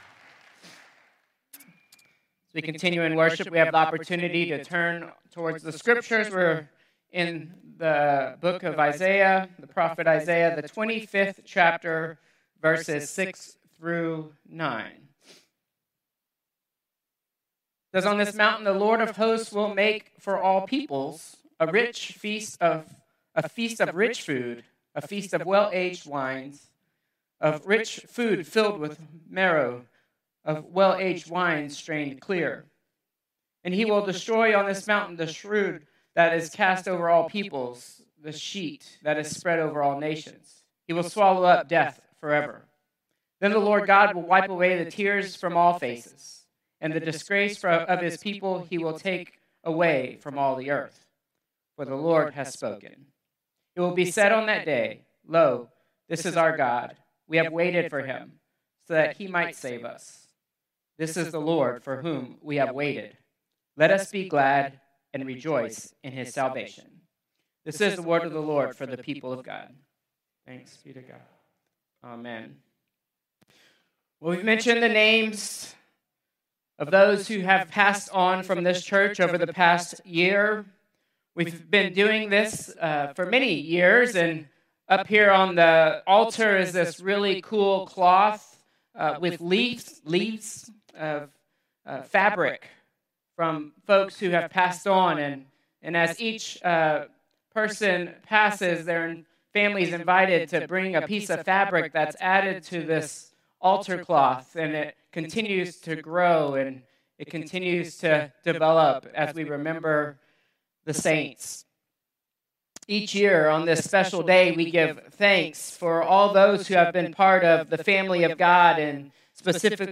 Contemporary Service 11/2/2025